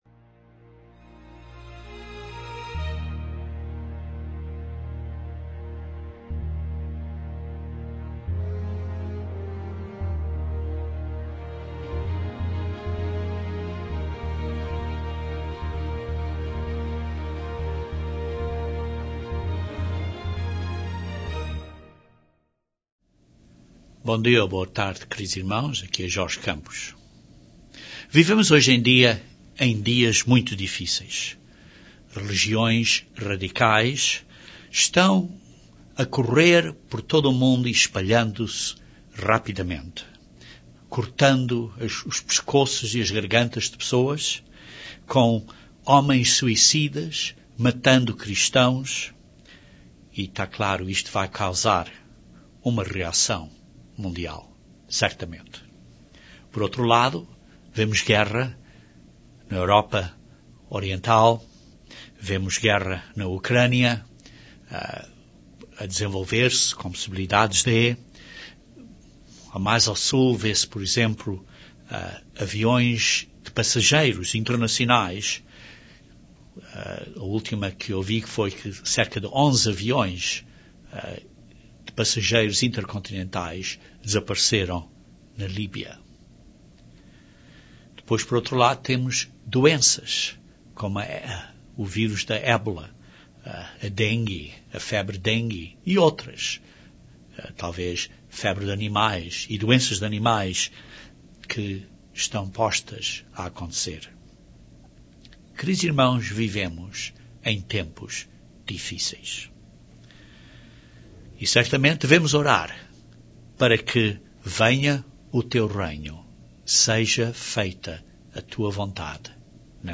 Este sermão é para lhe dar esperança nestes dias tristes, mas Deus requer que você se arrependa em fé e o demonstre na sua vida.